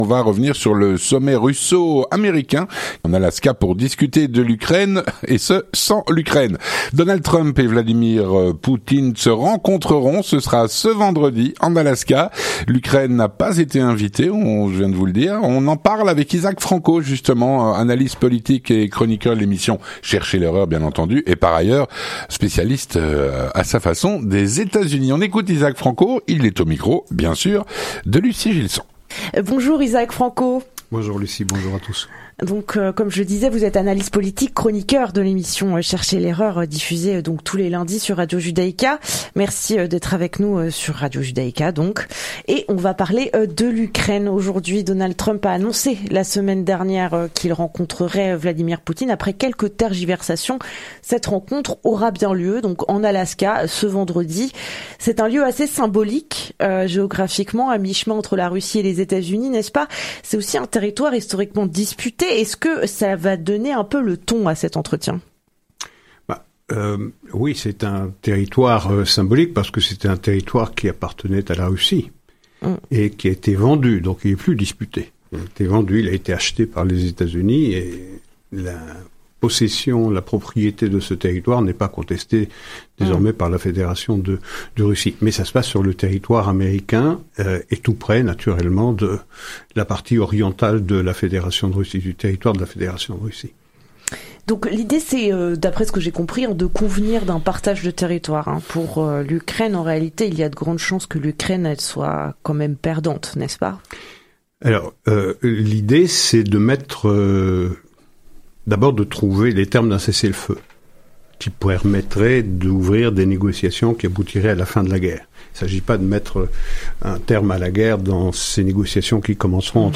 L'entretien du 18H - Le sommet russo-américain en Alaska pour discuter de l’Ukraine… sans l’Ukraine.